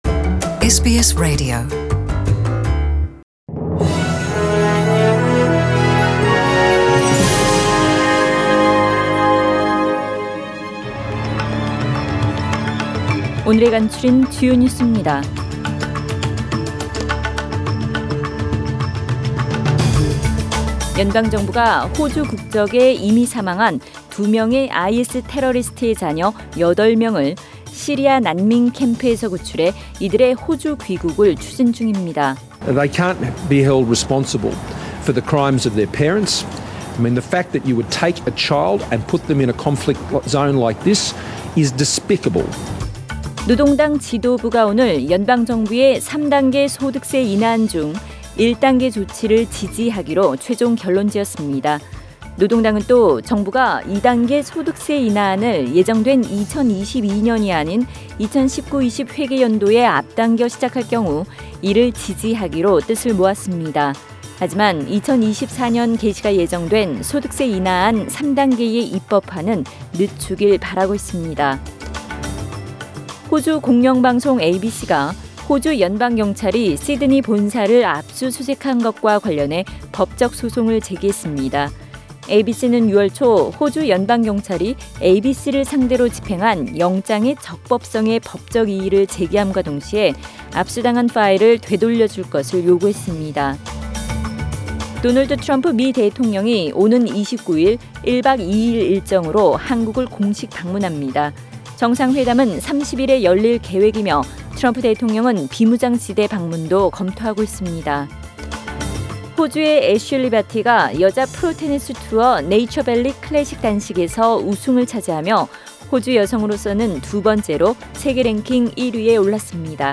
2019년 6월 24일 월요일 저녁의 SBS Radio 한국어 뉴스 간추린 주요 소식을 팟 캐스트를 통해 접하시기 바랍니다.